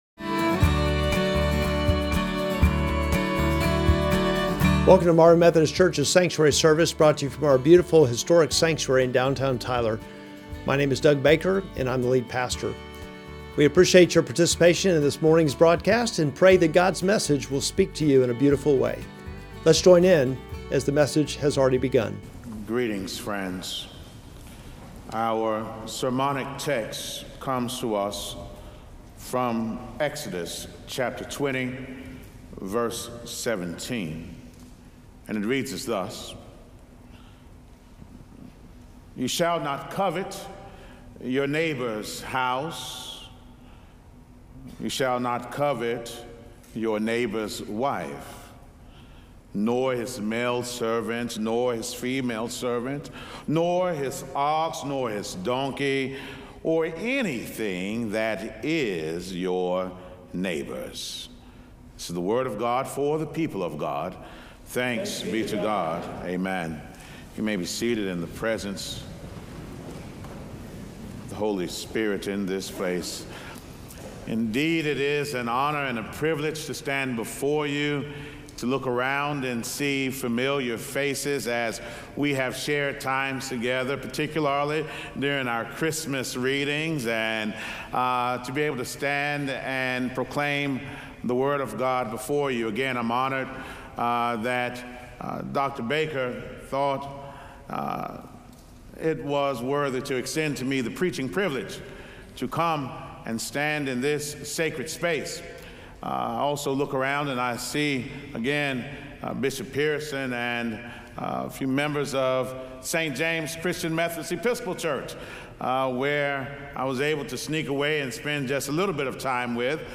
Sermon text: Exodus 20:17